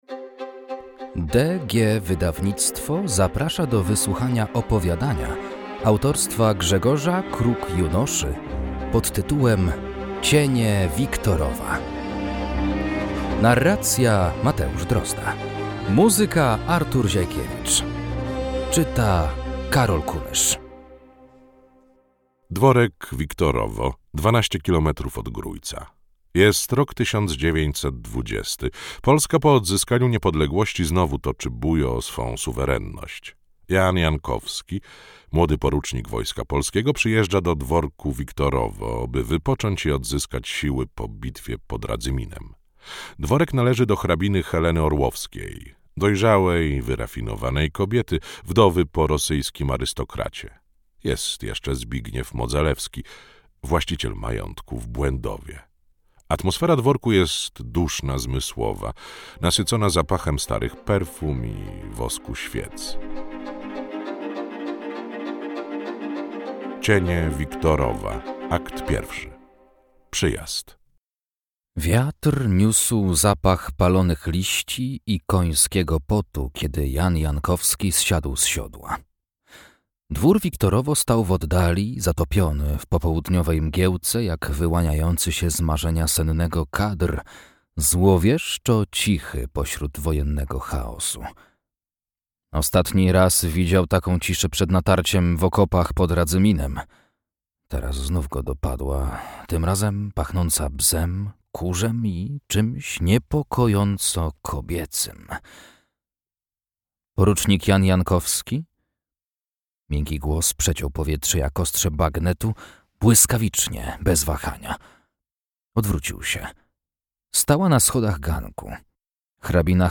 Cienie Wiktorowa - Grzegorz Kruk - audiobook